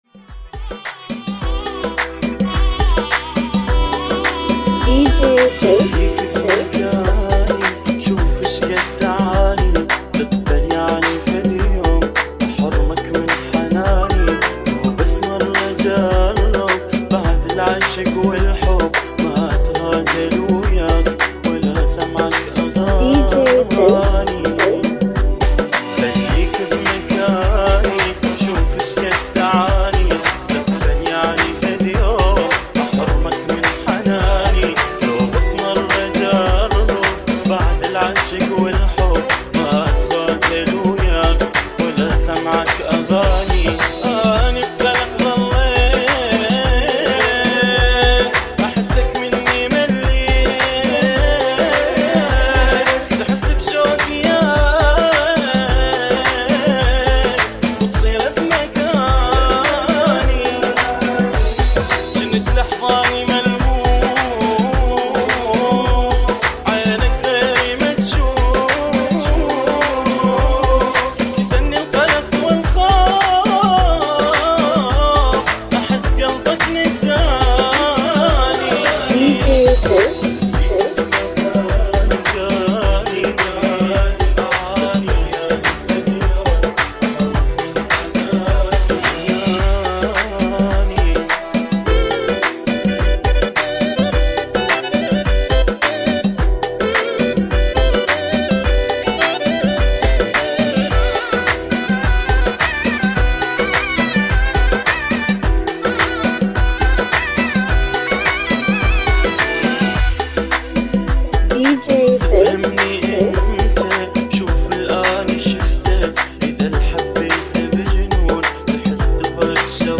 ريمكس